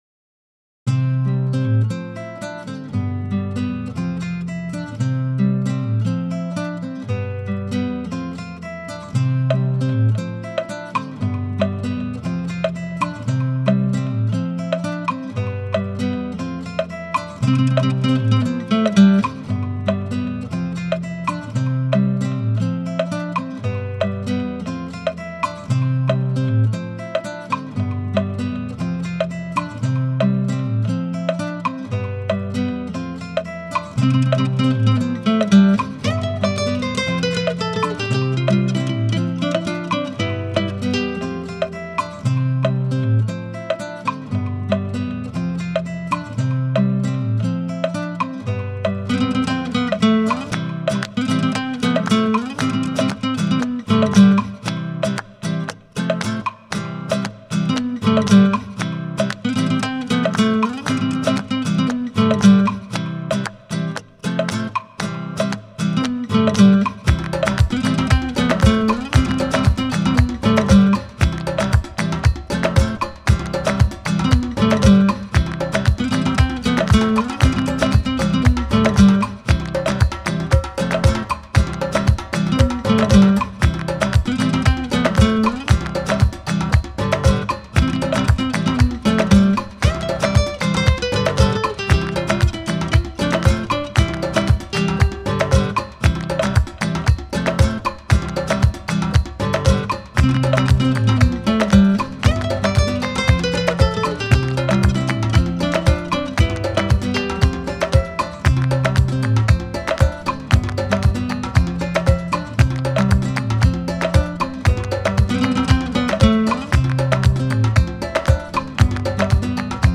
タグ: アフリカ、アコースティック、映画、ギター